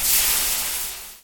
fizz.ogg